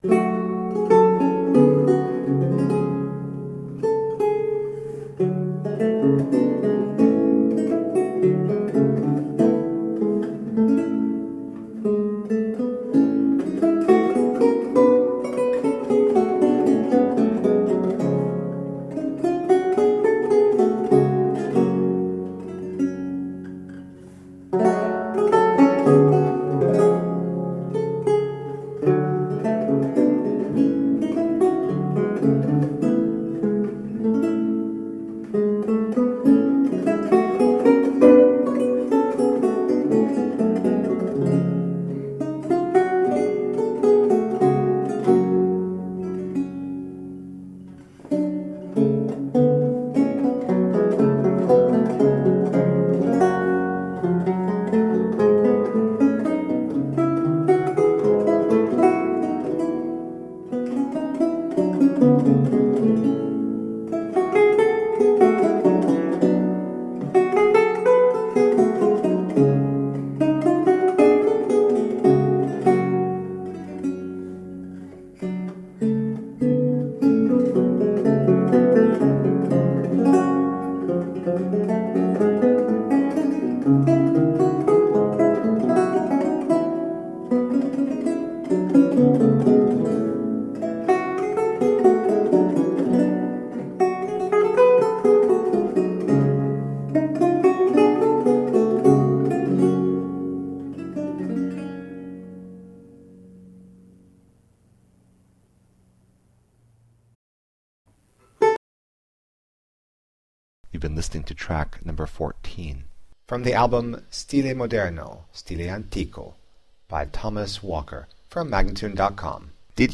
Lute music of 17th century france and italy
Classical, Baroque, Renaissance, Instrumental
Lute